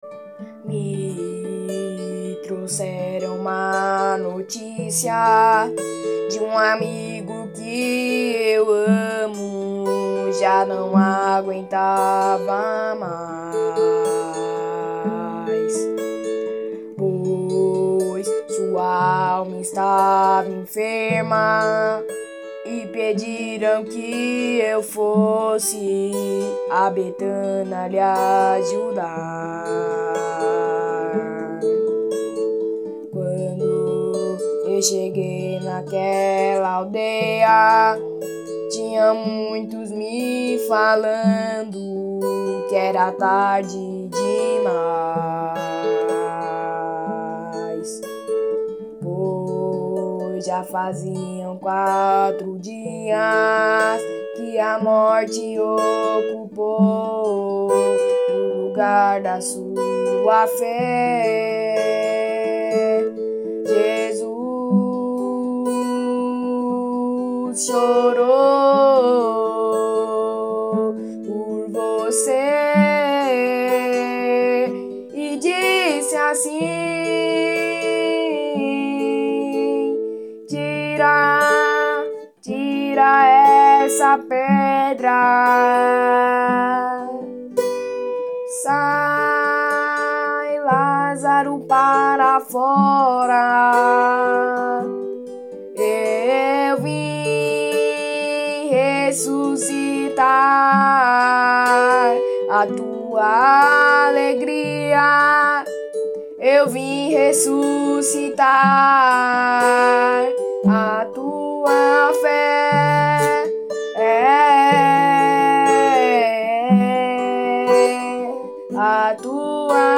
EstiloGuitar